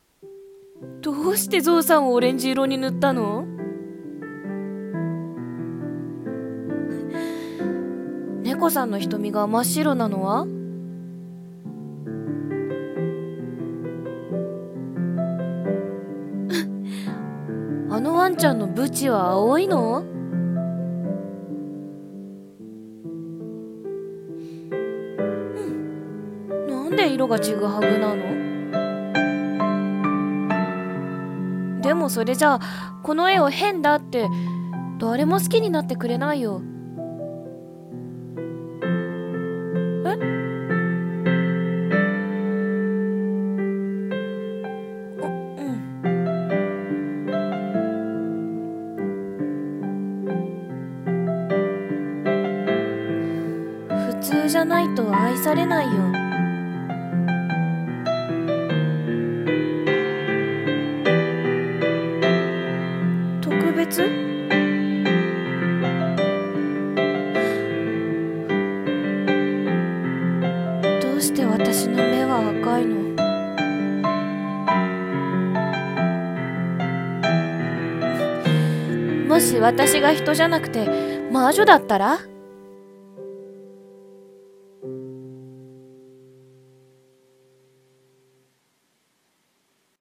二人声劇【とくべつないろ】